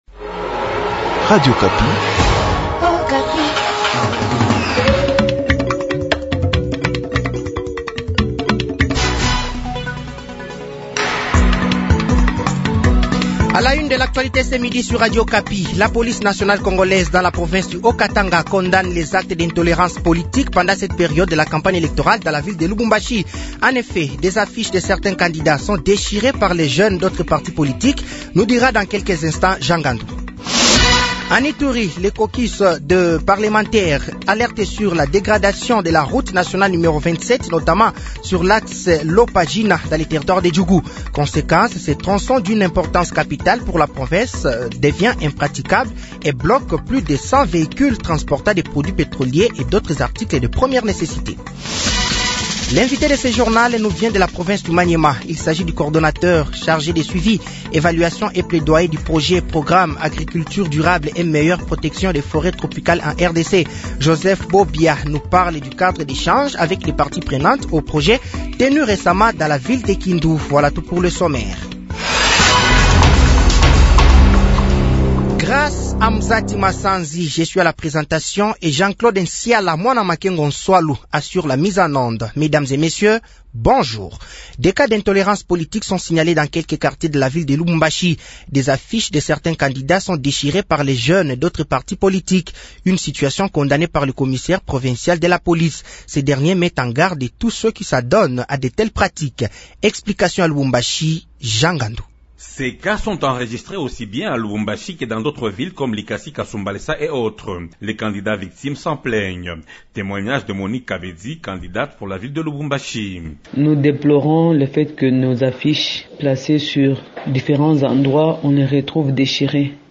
Journal midi
Journal français de 12h de ce jeudi 23 novembre 2023